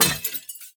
glass2.ogg